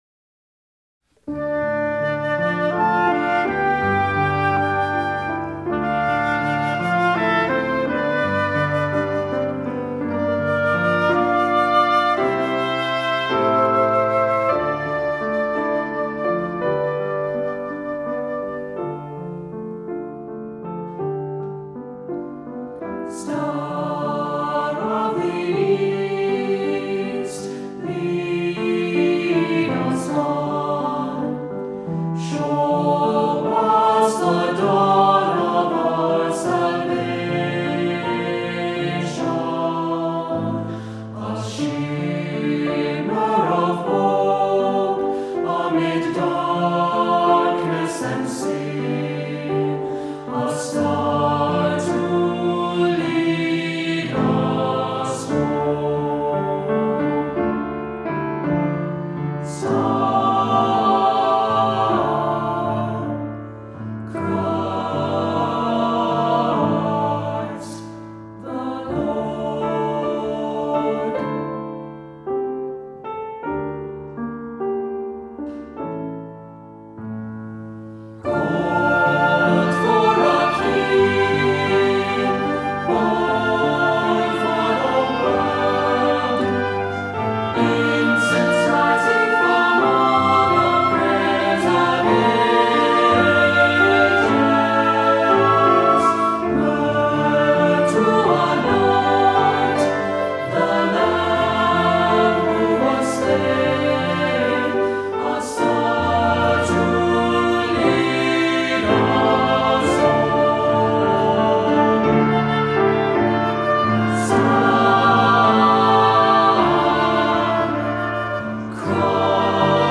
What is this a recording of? Voicing: Descant,SATB